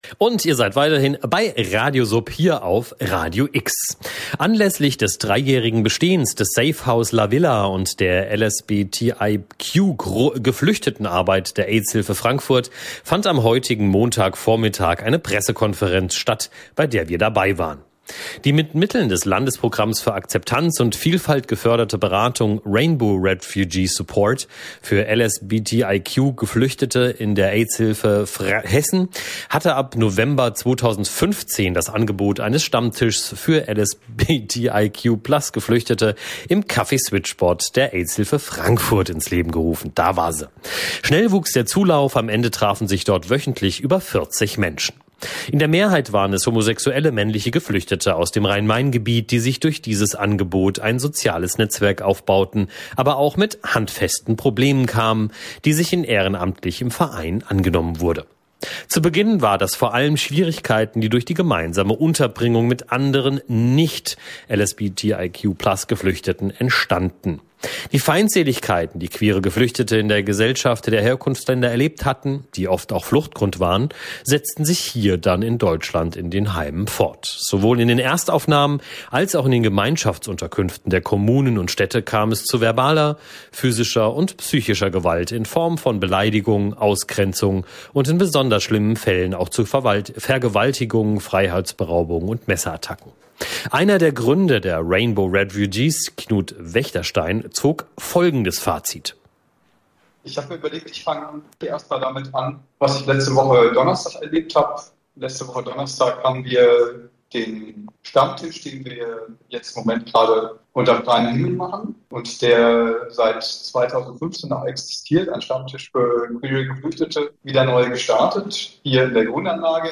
berichtet von der Pressekonferenz